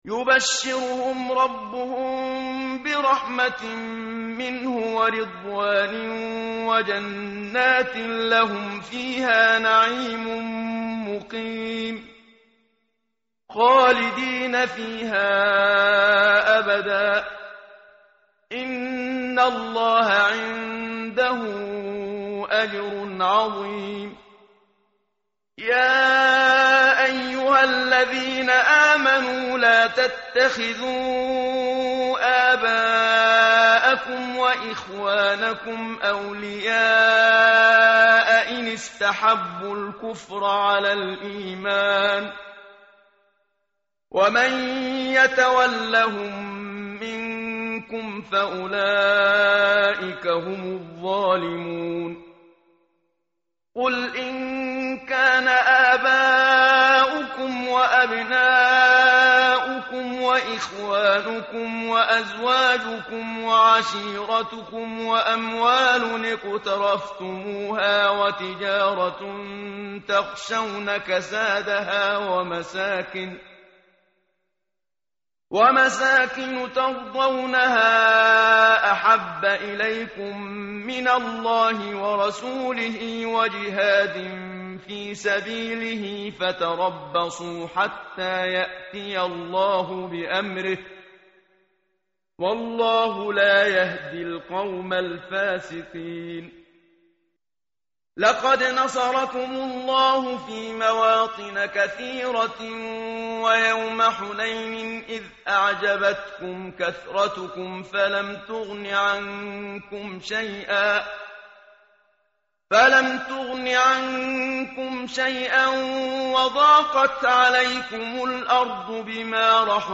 tartil_menshavi_page_190.mp3